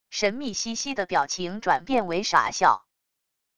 神秘兮兮的表情转变为傻笑wav音频